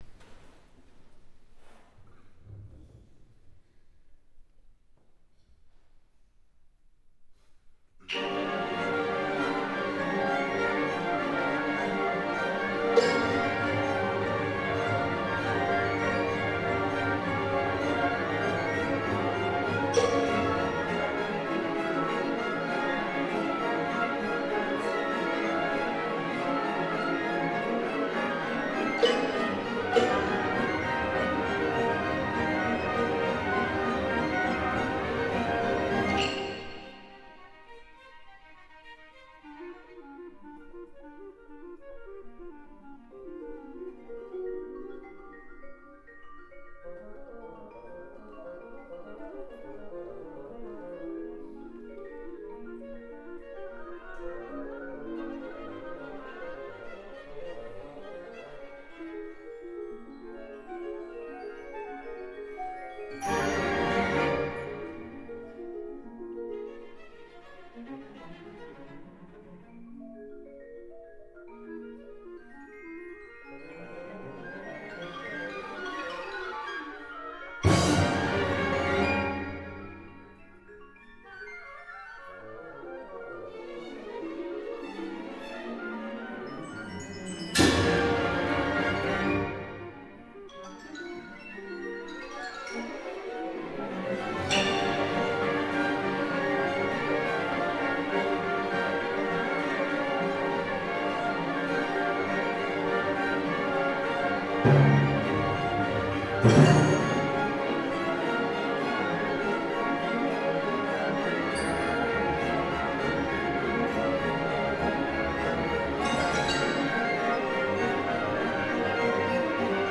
Four Pieces for Orchestra Four pieces for orchestra (Cztery utwory na orkiestre) were written during my studies at the Chopin Music Academy in Warsaw. The work is scored for a small orchestra comprising single woodwinds, little brass section, harp, strings and a lot of percussion. Each piece uses a different five-tone scale.
The final sound - B - has never been used during the entire piece except of this, last, final sound. 10' You need a RealAudio� player to hear the music: ���